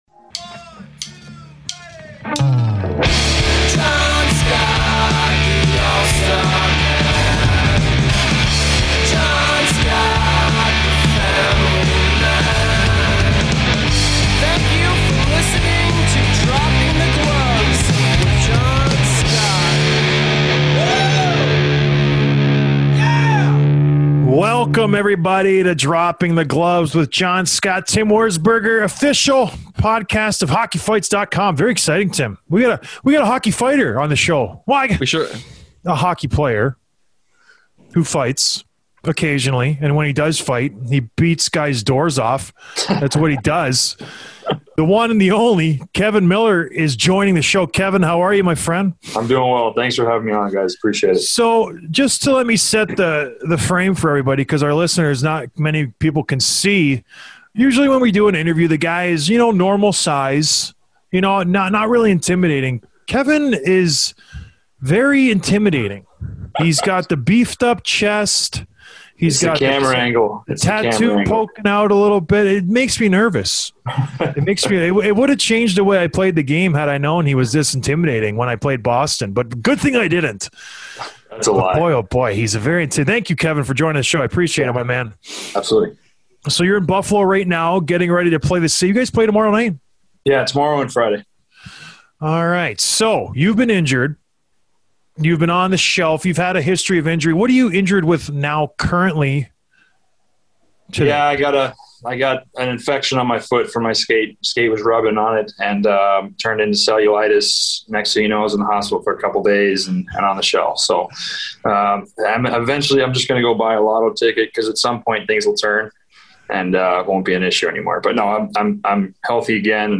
Interview with Kevan Miller, Boston Bruins